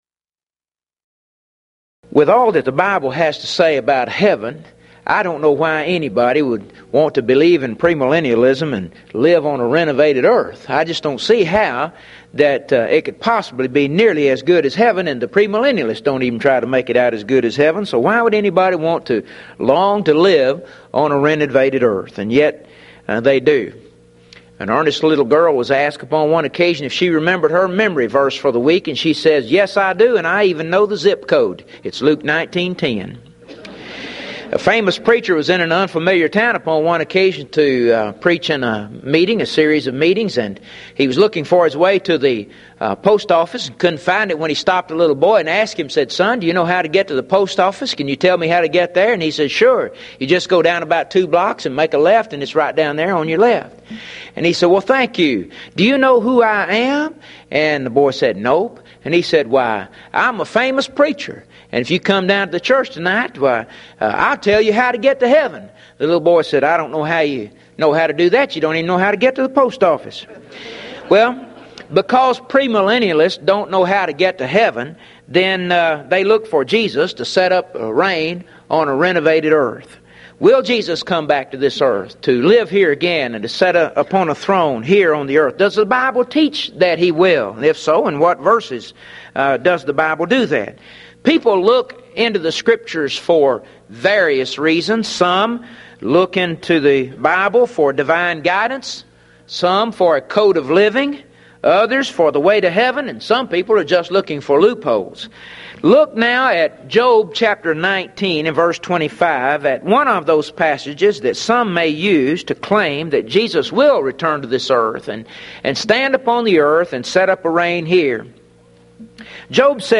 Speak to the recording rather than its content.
Event: 1995 Mid-West Lectures